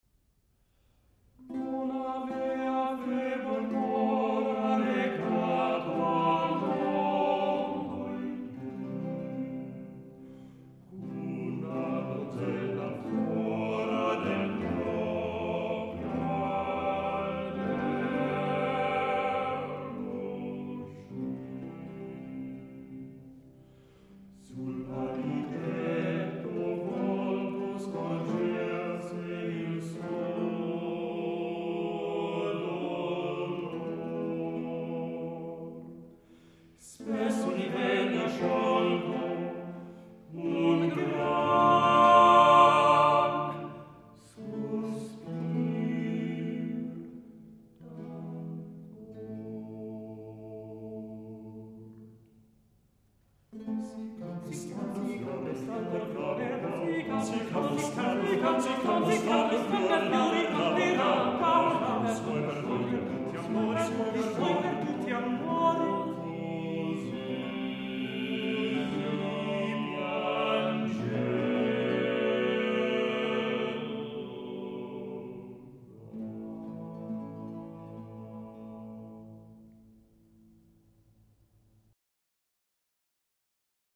Monteverdi, Lamento della ninfa (madrigale rappresentativo Libro VIII) prima parte.mp3